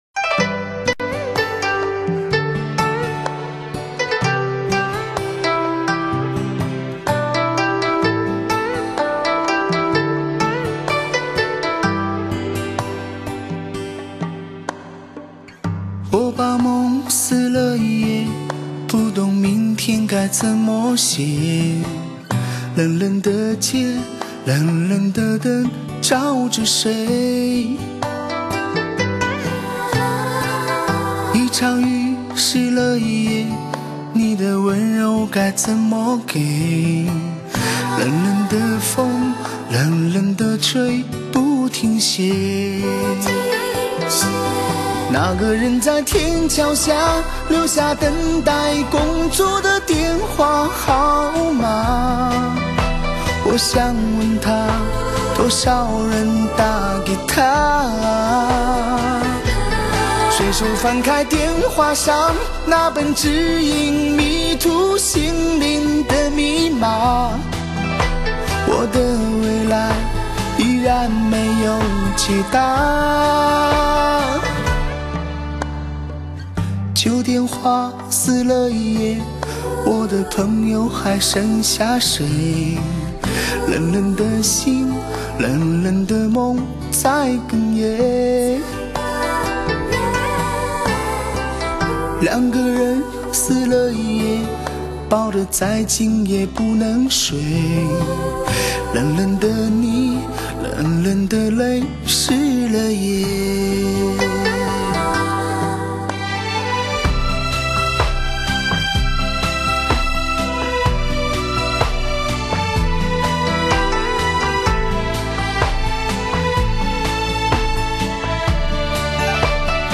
完美、沧桑的悲情男声